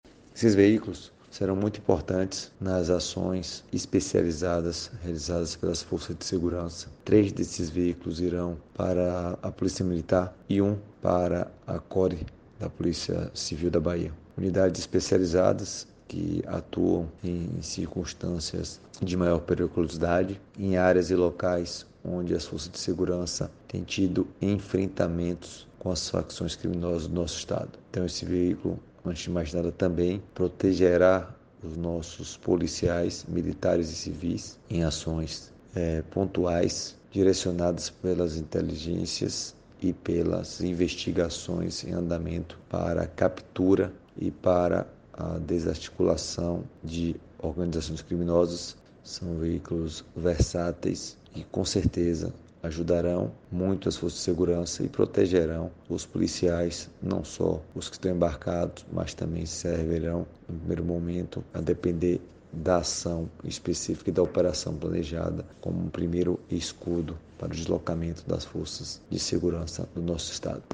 🎙Marcelo Werner, secretário da Segurança Pública